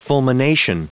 Prononciation du mot fulmination en anglais (fichier audio)
Prononciation du mot : fulmination